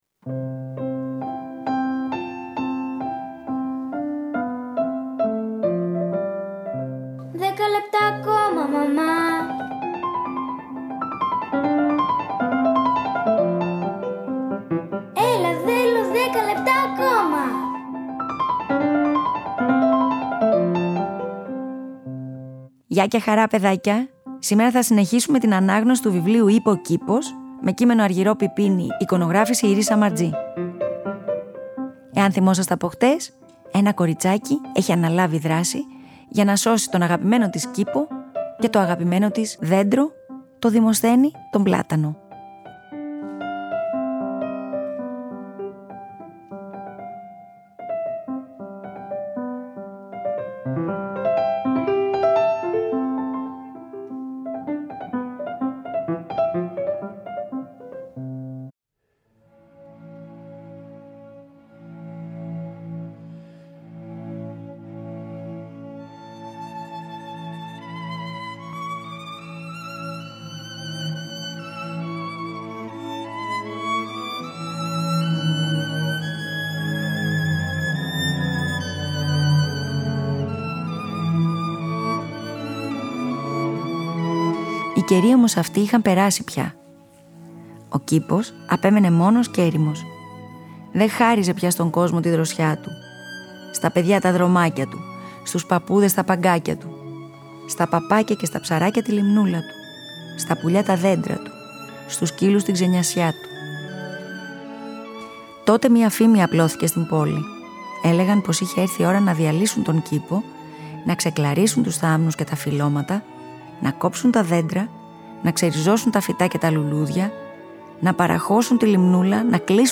«Είπε ο κήπος…» με κείμενο Αργυρώ Πιπίνη – Argyro Pipini , εικόνες Iris Samartzi illustrator , από τις Εκδόσεις Πατάκη-Patakis Publishers Θα το διαβάσουμε σε δύο μέρη.